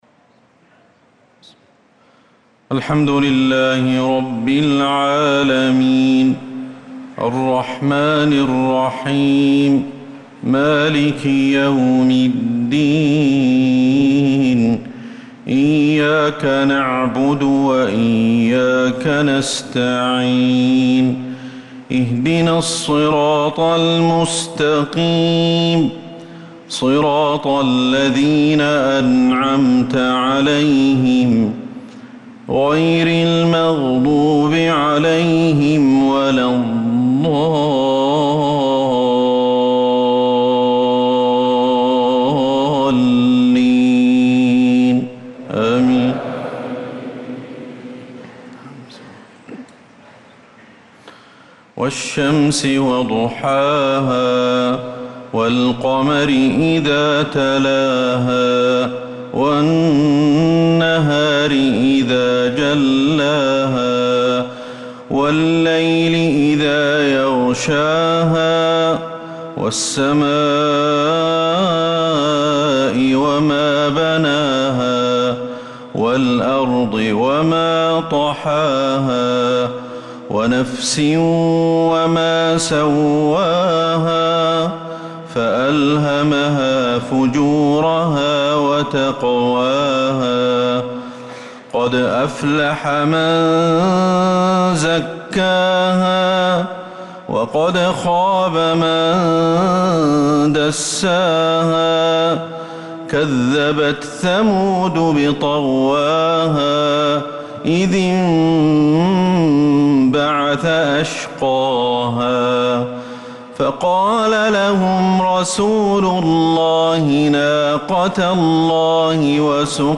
صلاة العشاء للقارئ أحمد الحذيفي 15 ذو الحجة 1445 هـ